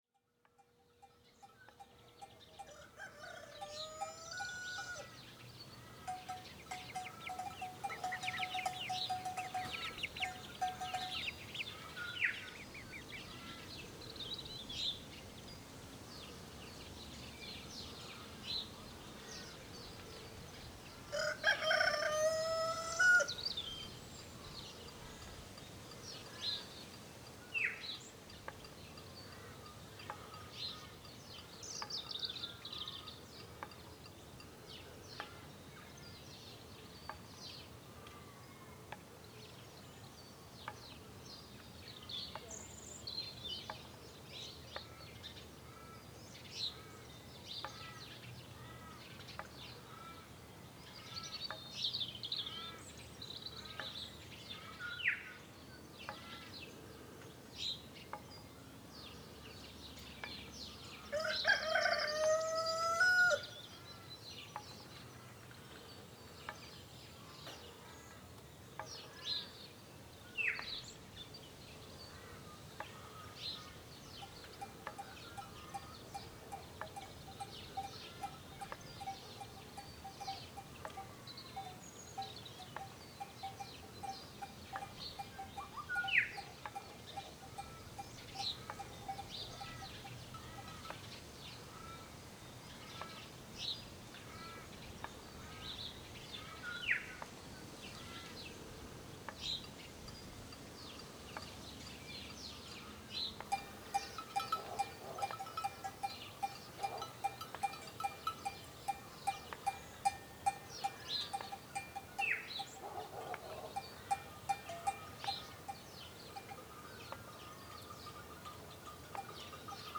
AMB_Scene05_Ambience_RS.ogg